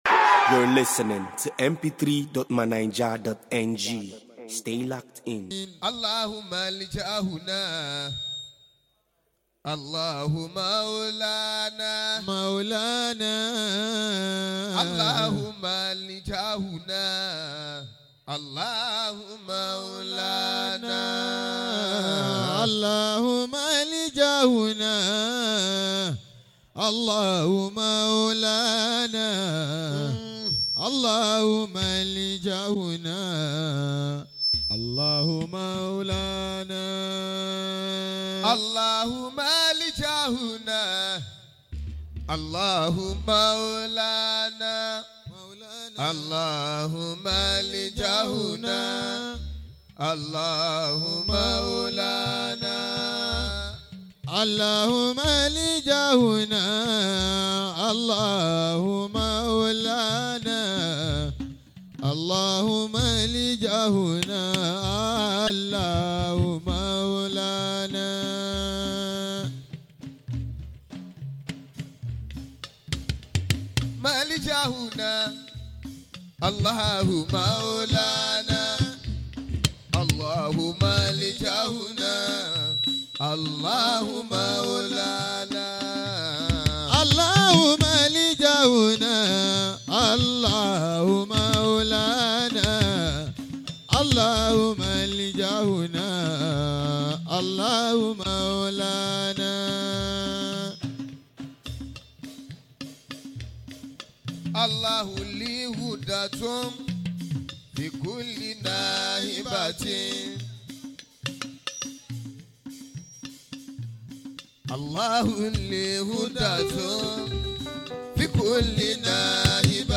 At Awoshifila Day 2023